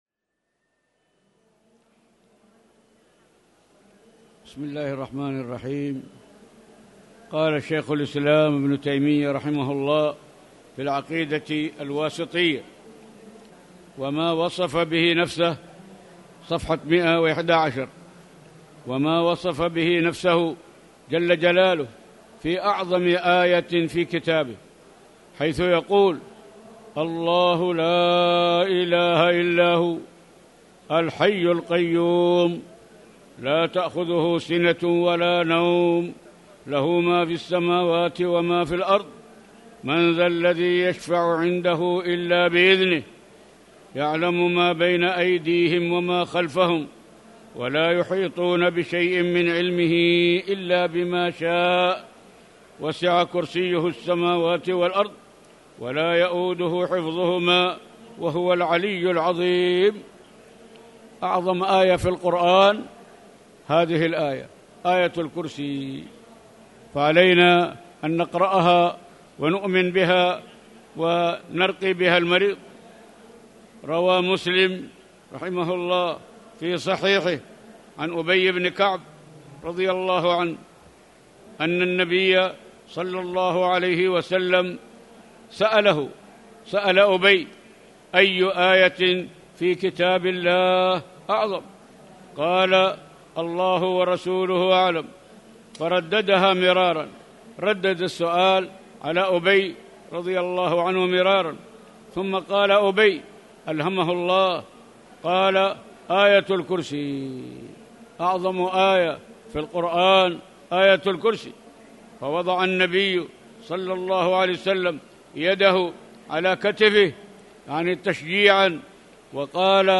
تاريخ النشر ٩ صفر ١٤٣٩ هـ المكان: المسجد الحرام الشيخ